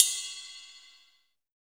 D2 RIDE-04-L.wav